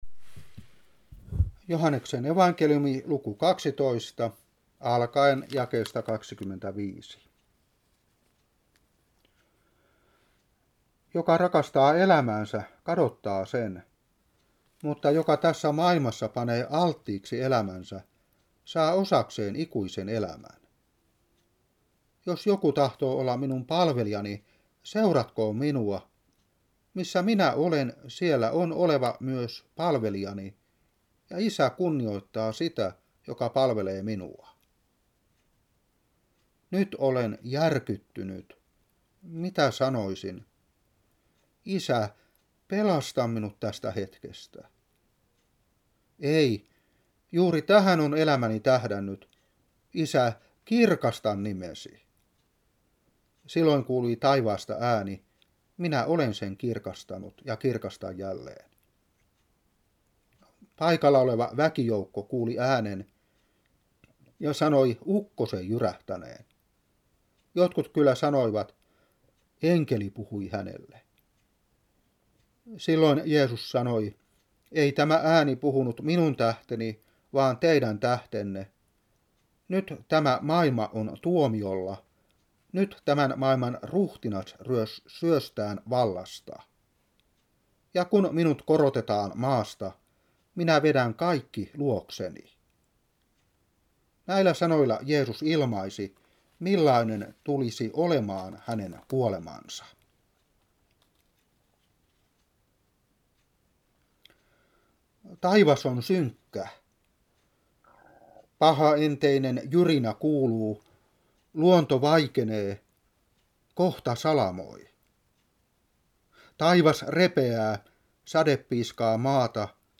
Seurapuhe 2011-3. Joh.12:25-33.